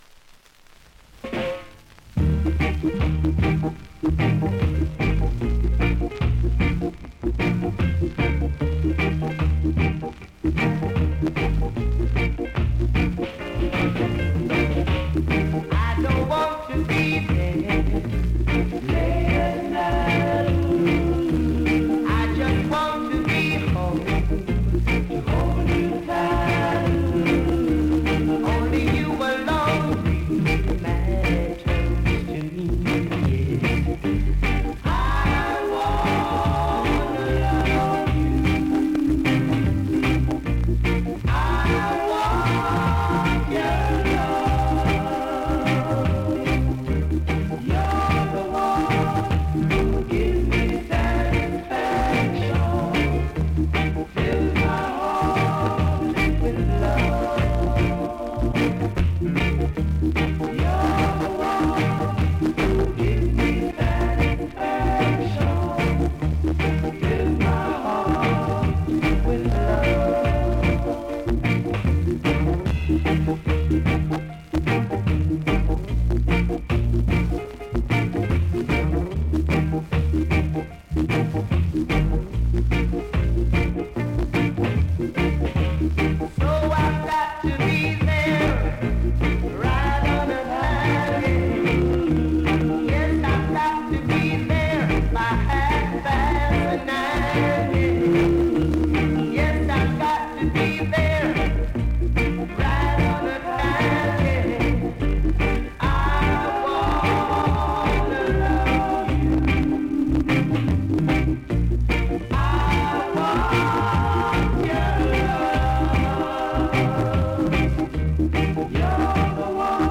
文章で伝わると良いのですが、２カ所盤の端からヒビに見える部分ありますが実際は割れてないので音にも影響ありません。